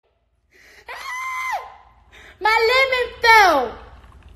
Play, download and share lemon fell original sound button!!!!
ahhh-my-lemon-fell.mp3